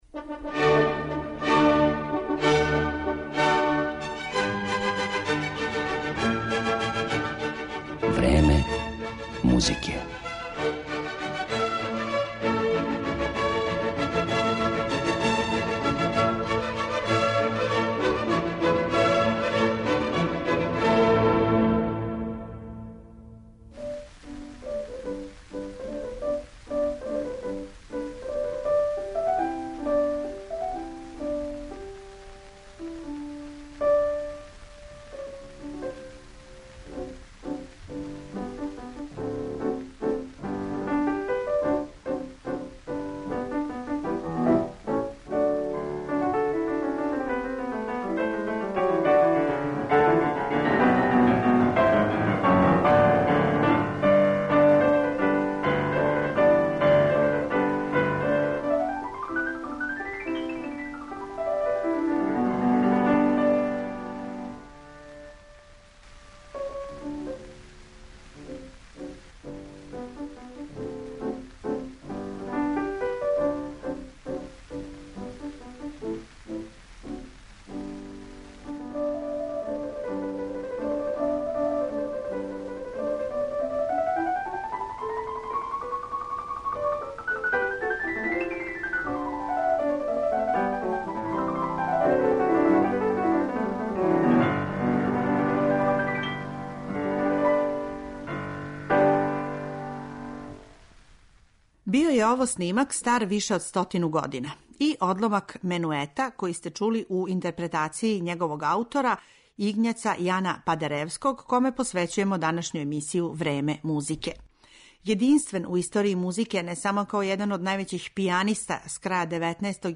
за клавир.